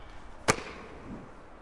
描述：为了接住篮球的球而产生的声音。